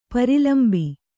♪ pari lambi